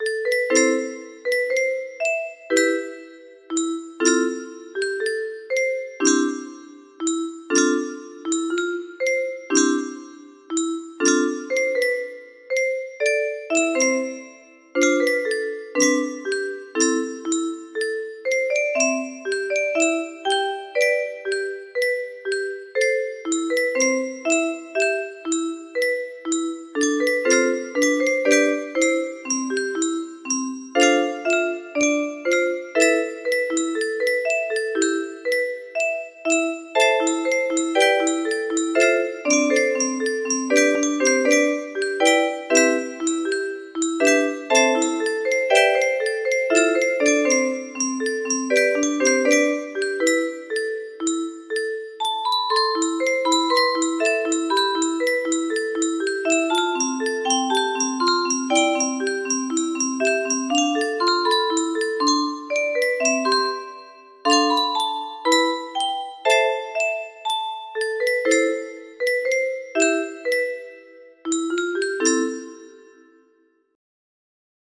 Kikkerland 15 music boxes More